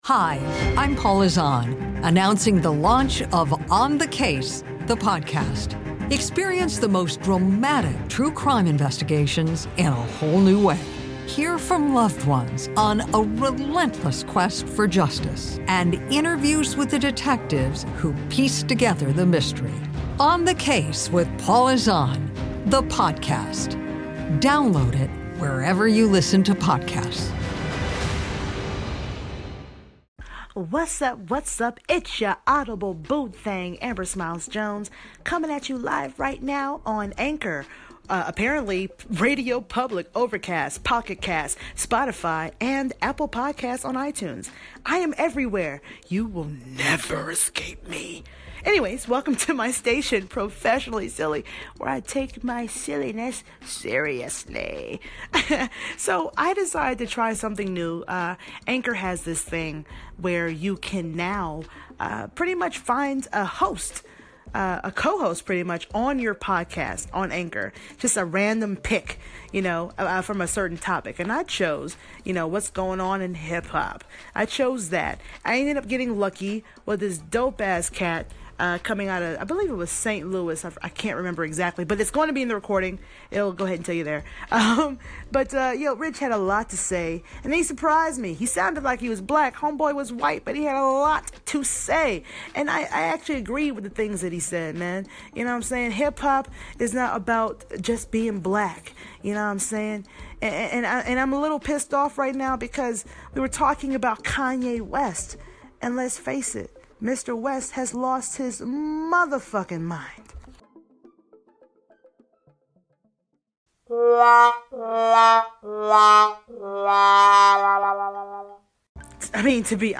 I used the new Anchor function that picks a random co-host for me. And I got lucky...met a cool dude from New Orleans (Nawlins) with an interesting view on HipHop and Kanye’s crazy ass!